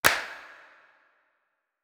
TC2 Clap11.wav